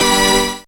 37cp01syn-g.wav